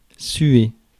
Ääntäminen
IPA: [sɥe]